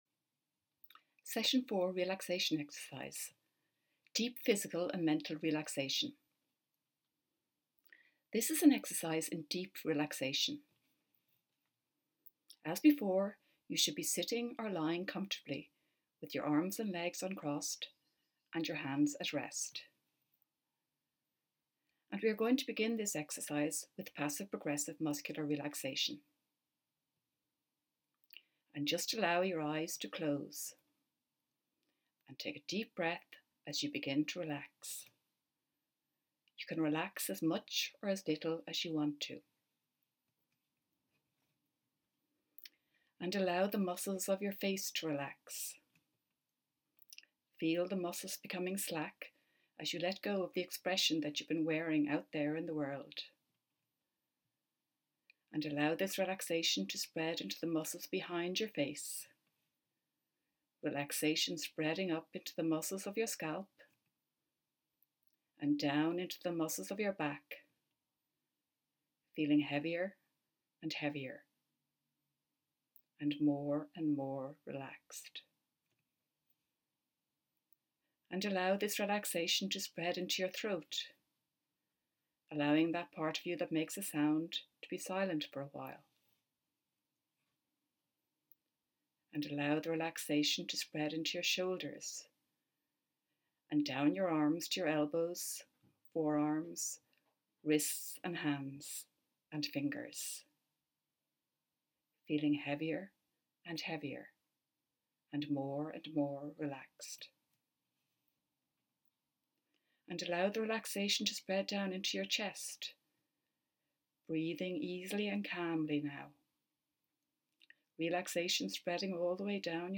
Relaxation Exercise